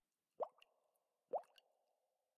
Minecraft Version Minecraft Version snapshot Latest Release | Latest Snapshot snapshot / assets / minecraft / sounds / ambient / underwater / additions / bubbles4.ogg Compare With Compare With Latest Release | Latest Snapshot
bubbles4.ogg